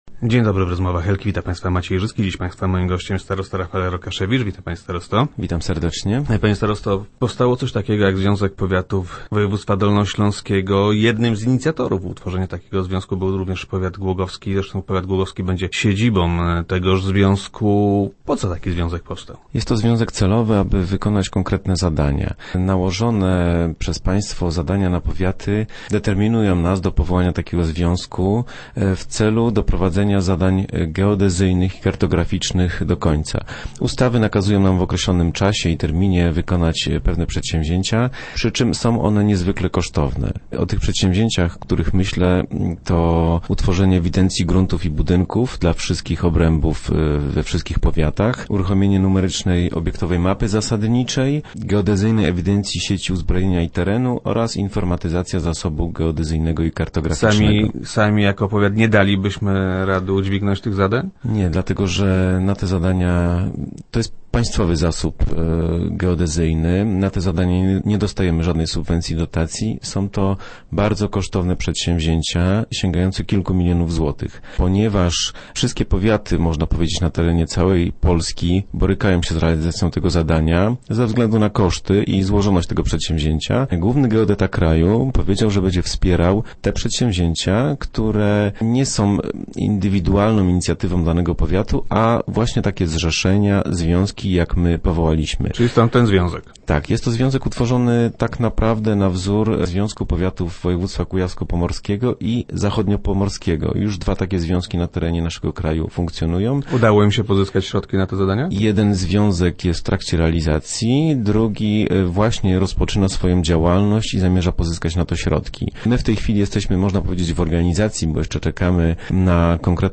- Ustawy nakazują nam w określonym terminie wykonać ewidencję gruntów i budynków, uruchomienie numerycznej mapy zasadniczej, stworzenie geodezyjnej ewidencji sieci i terenu oraz informatyzację zasobu geodezyjnego i kartograficznego. Są to zadania, na które potrzeba milionów złotych, a na które nie otrzymujemy żadnych subwencji, czy też dotacji - mówił na radiowej antenie starosta Rokaszewicz.